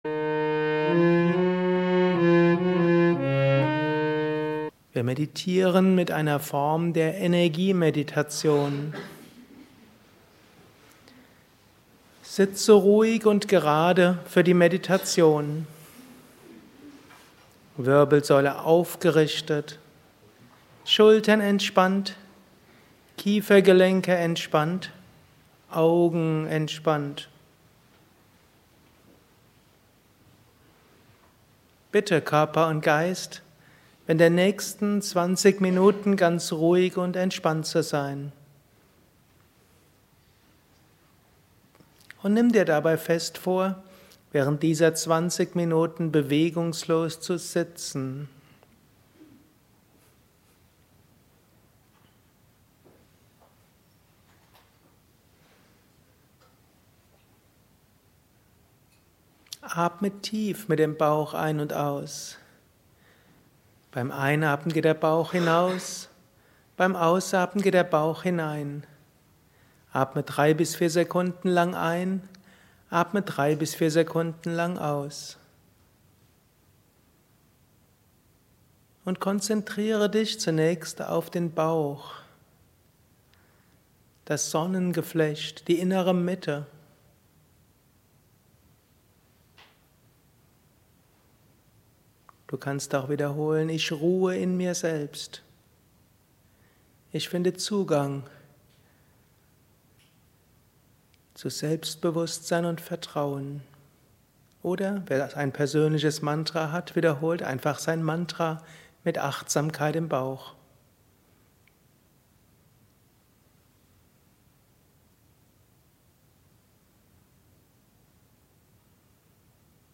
Meditation mit Energietechniken für erfahrene Meditierende – nicht für Anfänger. Insbesondere geeignet für Menschen, die schon eine Weile meditieren und die Tiefe und Kraft ihrer Meditation ausbauen wollen. Mitschnitt aus einem spirituellen Retreat im Yoga Vidya Ashram Bad Meinberg.
133_energie-meditation.mp3